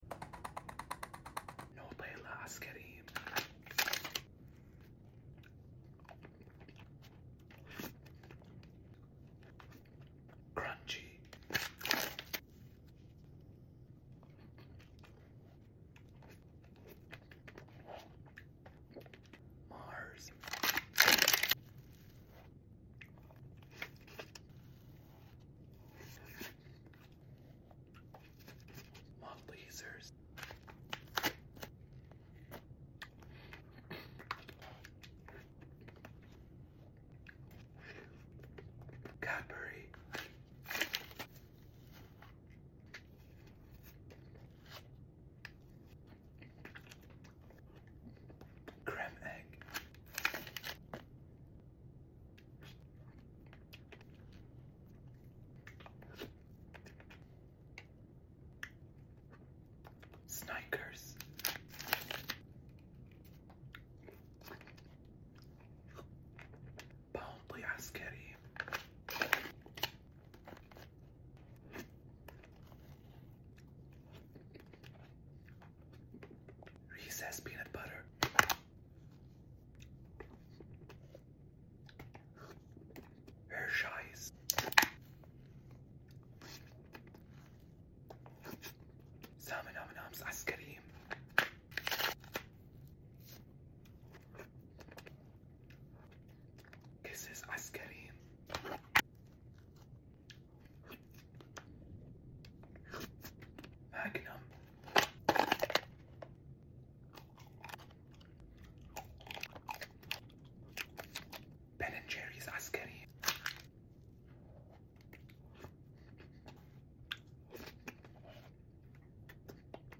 Food ASMR (Part 545) Sound Effects Free Download